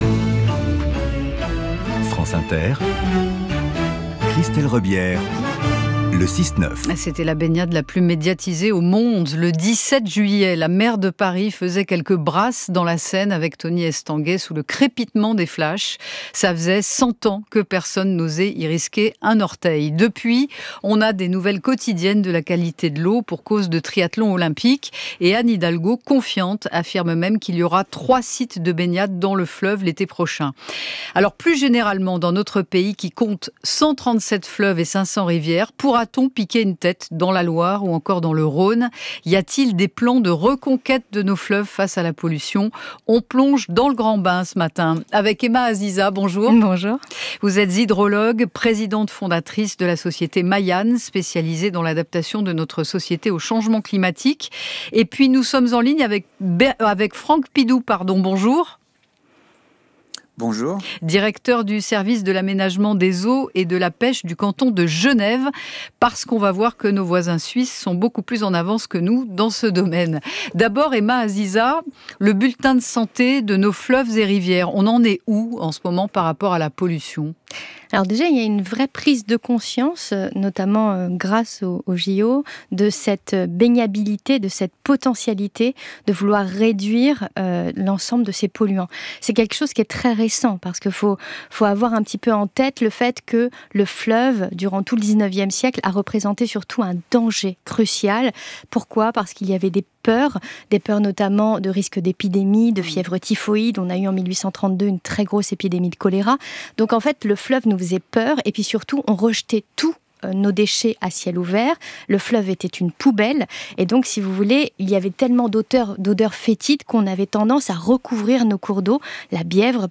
Comment se réapproprier nos cours d'eau ? Entretien